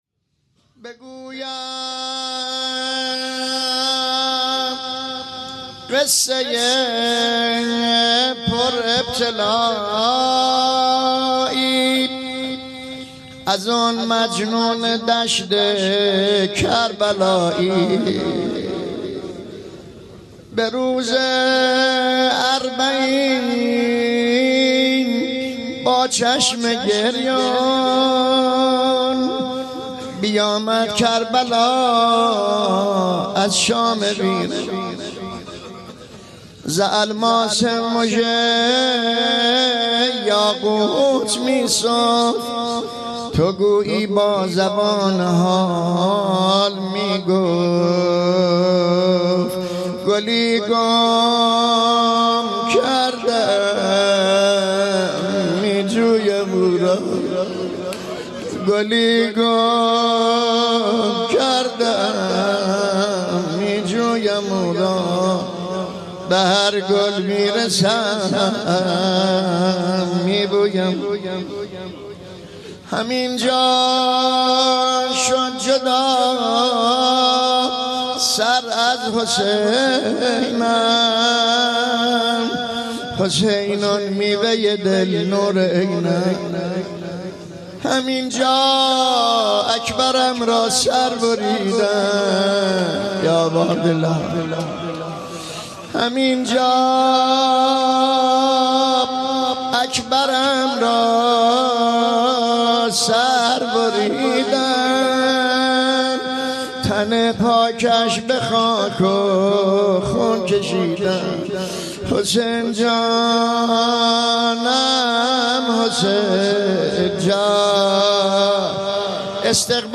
بگویم قصه ی پر ابتلایی | پیش منبر